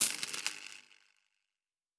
Sci-Fi Sounds / Electric / Spark 20.wav
Spark 20.wav